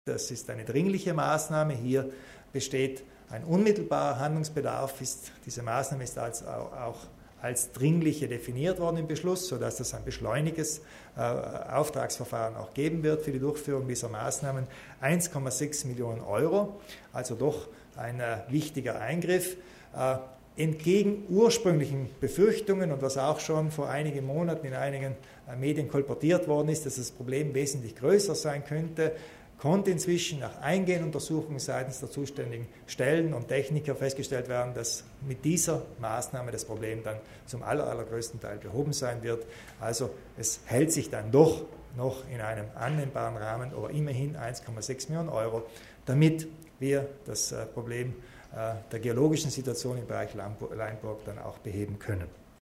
Landeshauptmann Kompatscher zu den Sicherungsarbeiten im Gelände der Laimburg